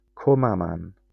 Cwmaman (Welsh pronunciation: [kʊmˈaman]